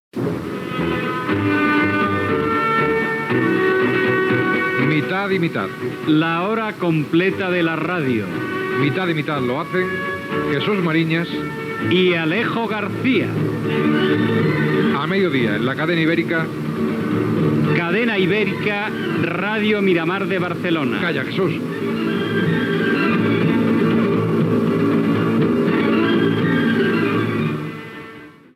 Promoció del programa
Entreteniment